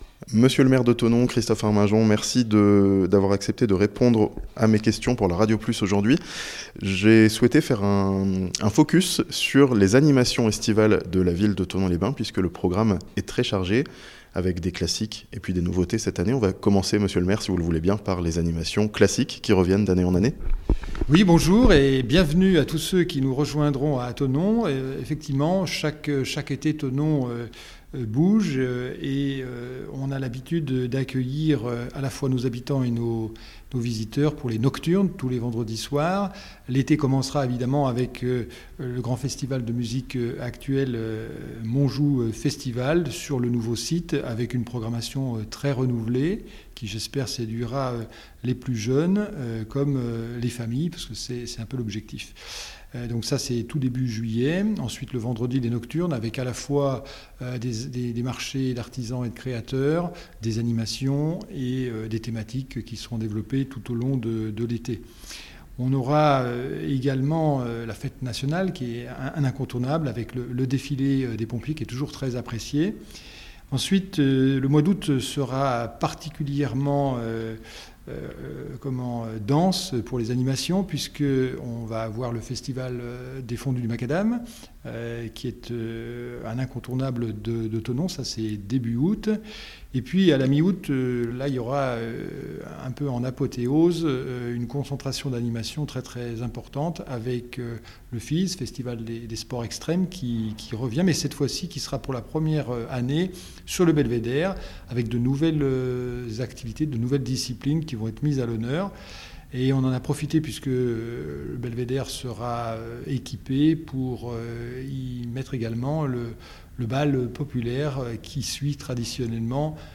A Thonon, de nombreuses animations sont prévues pour cet été 2025 (interview)